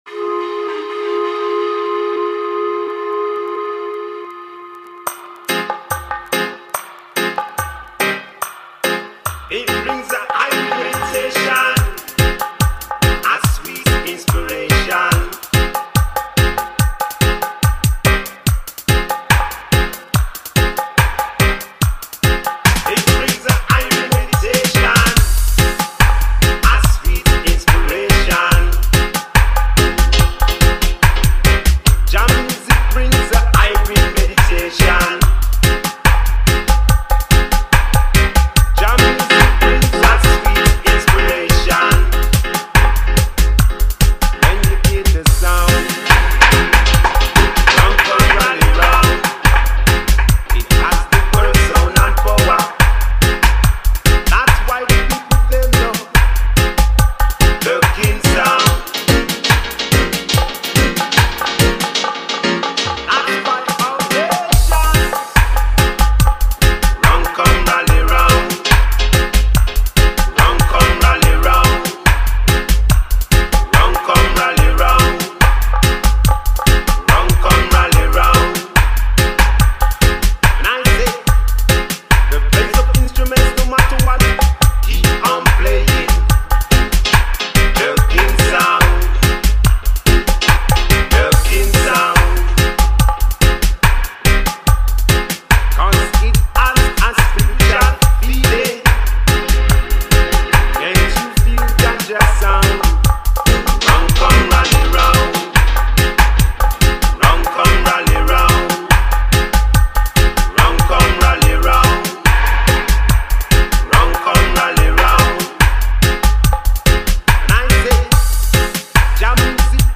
vocals and akete drum